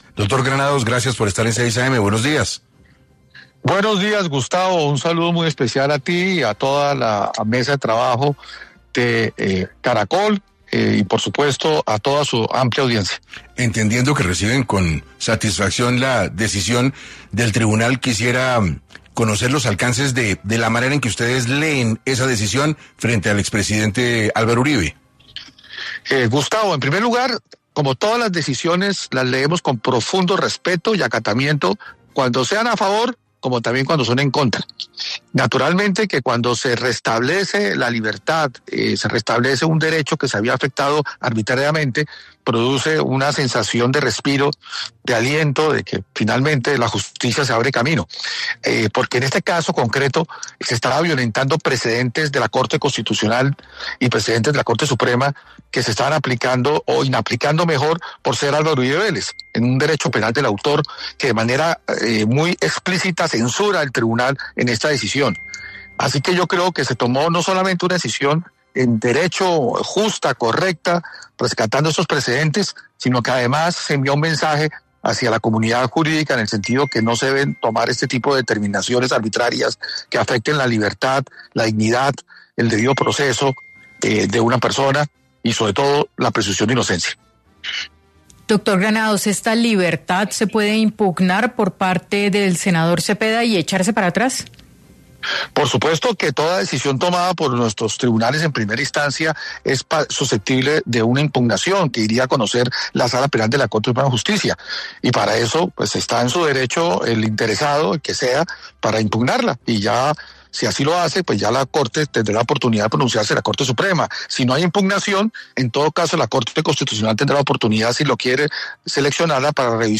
En 6AM de Caracol Radio, el abogado Jaime Granados afirmó que con esto se está haciendo justicia y que se esta respetando el debido proceso.